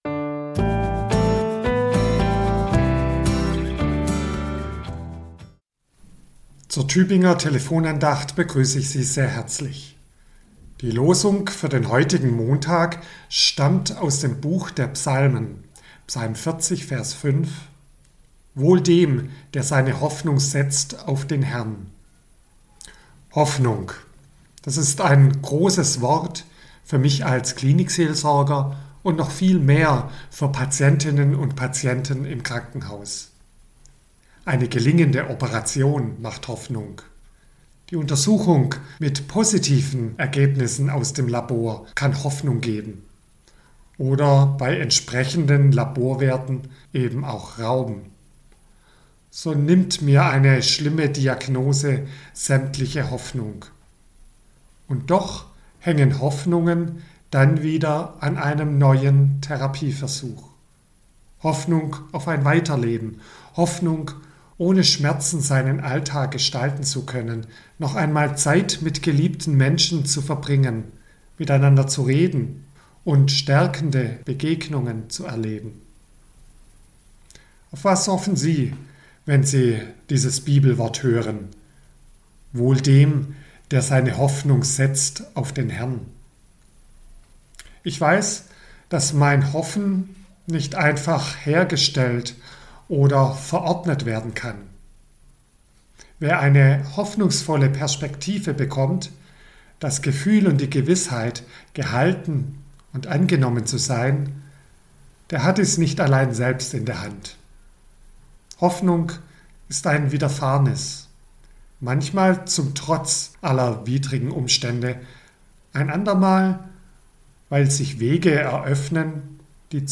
Andacht zur Tageslosung